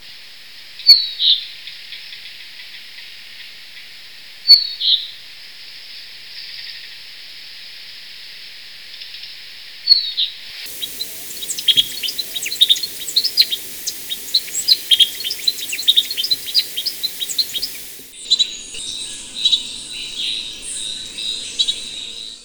Antillean Euphonia
El llamado consiste de una o dos notas "chit-it" que suenan como minúsculas campanas. El canto es un gorjeo ventrílocuo, recordando el canto de Carduelis tristis ("American Goldfinch" en inglés) de América del Norte.